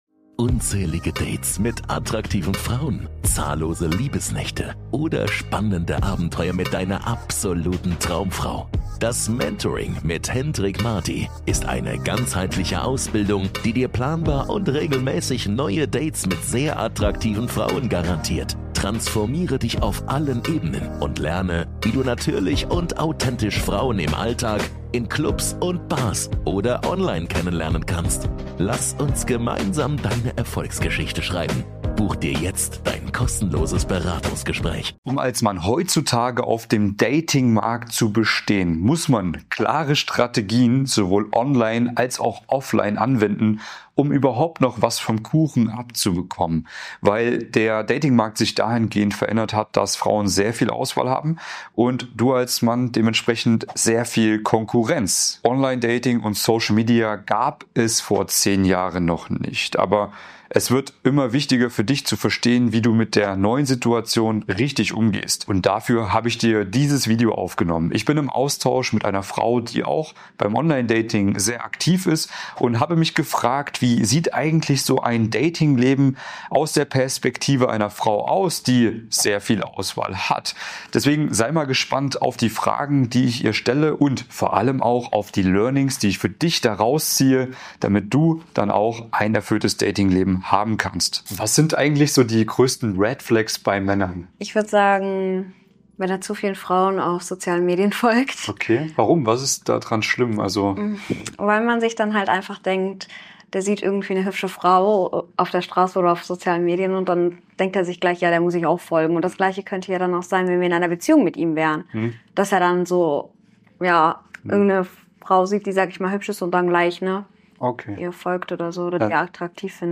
Achtung, Dating-Fehler! In diesem exklusiven Interview spreche ich mit einer attraktiven Frau über die größten Red Flags und häufigsten Fehler, die Männer beim Online Dating, Frauen ansprechen und Flirten machen.